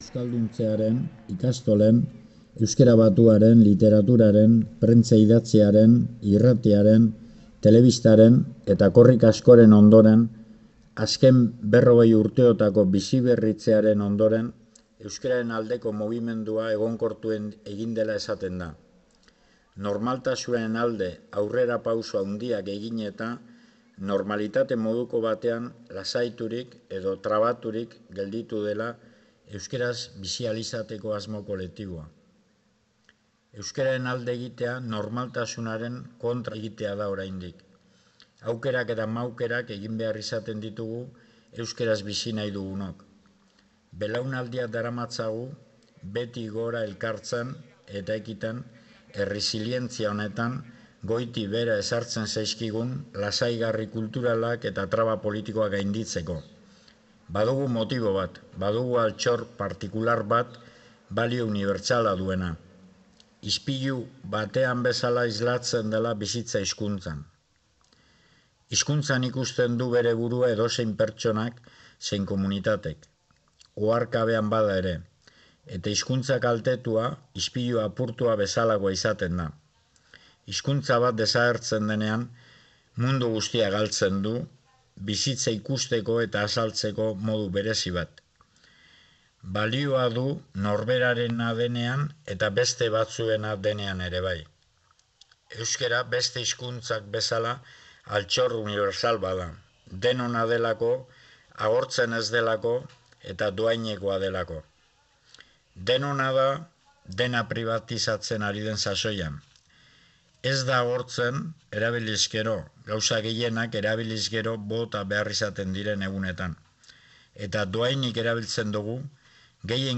Aurten, Joseba Sarrionaindiak idatzi baitu eta bere ahotsean grabatutako mezua entzun ahal izan da.